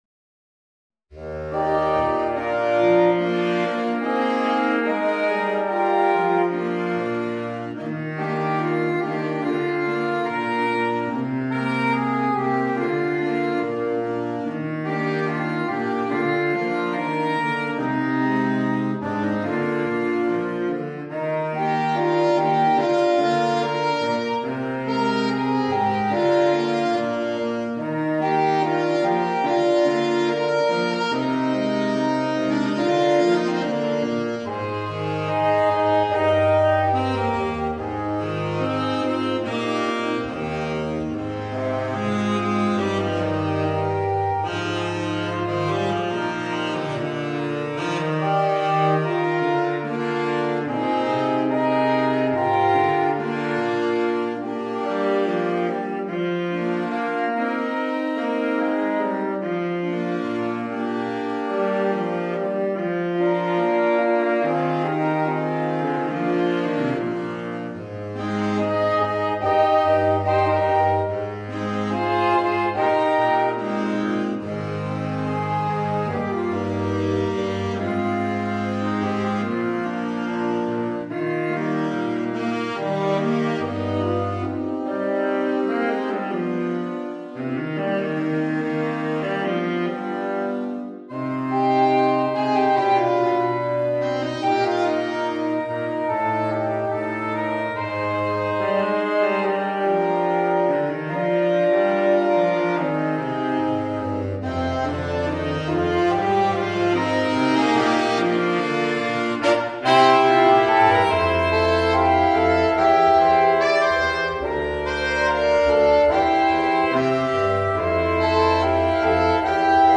(for Saxophone Quartet SATB or AATB)
easy arrangement